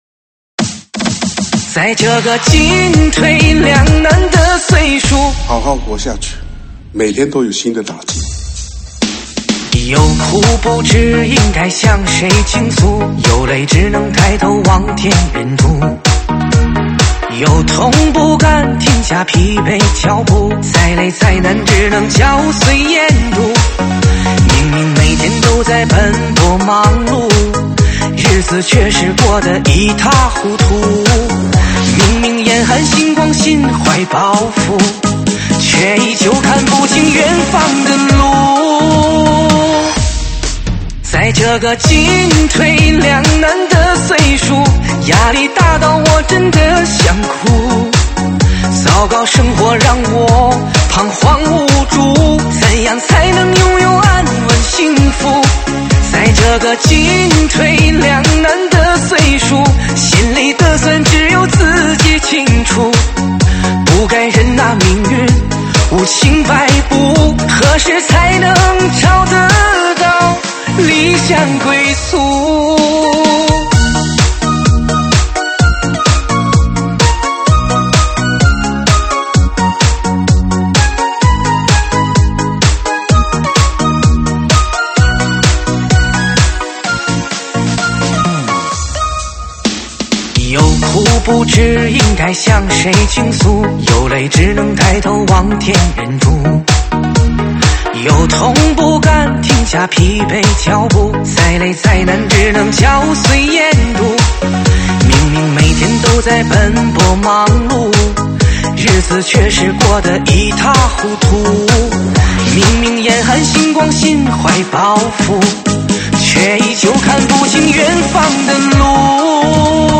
[现场串烧]
舞曲类别：现场串烧